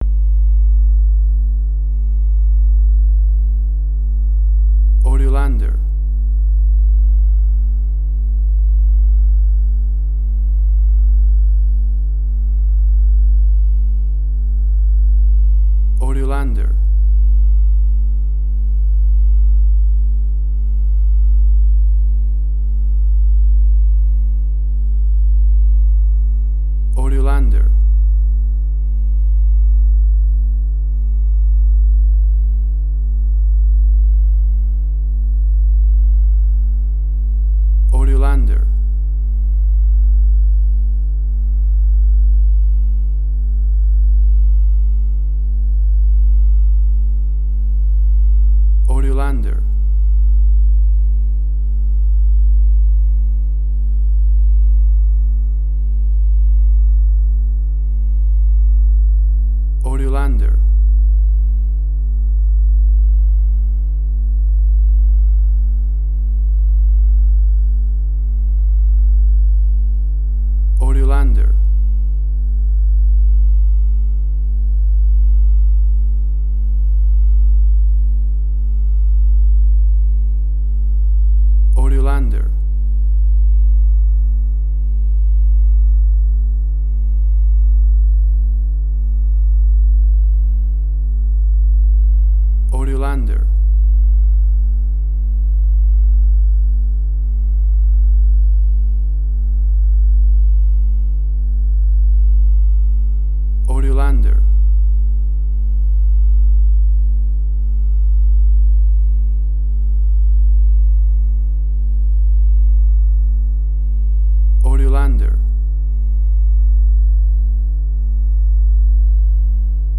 IDM, Glitch.
emotional music
WAV Sample Rate: 16-Bit stereo, 44.1 kHz